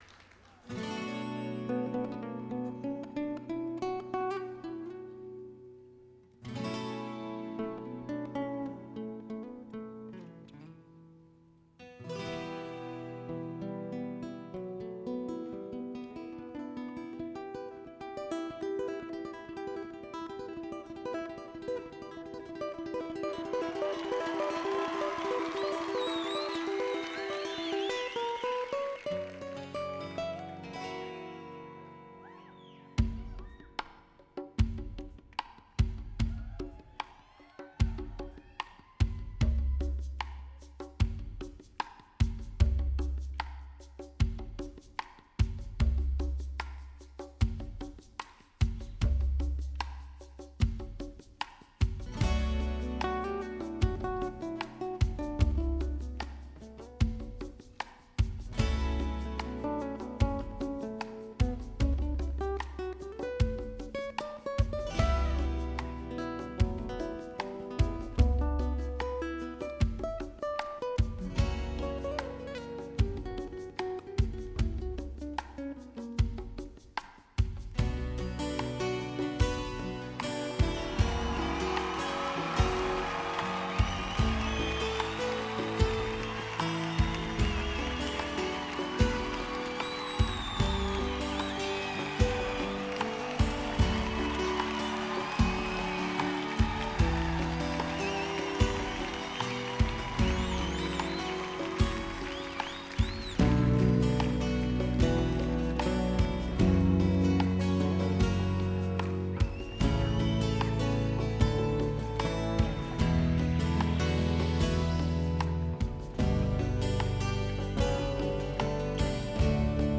DTS-ES6.1
都是DTS多维立体音场带来的极品体验。